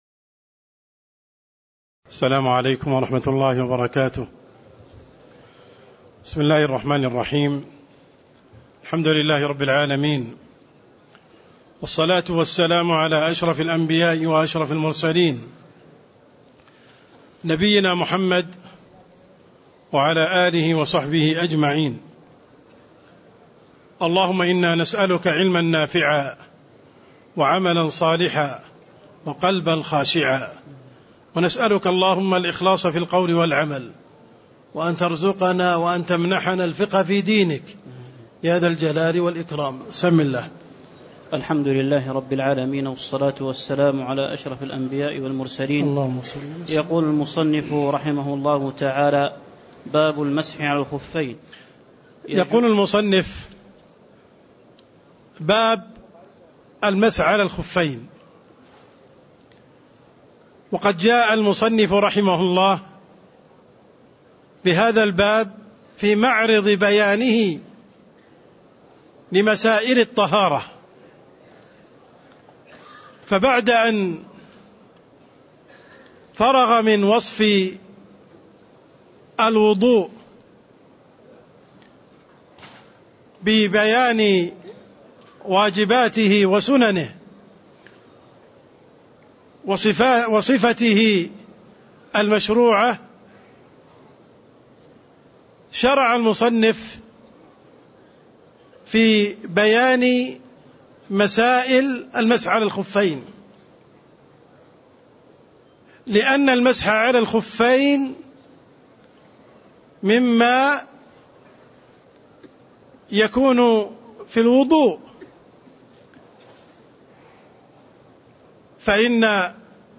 تاريخ النشر ٨ جمادى الآخرة ١٤٣٥ هـ المكان: المسجد النبوي الشيخ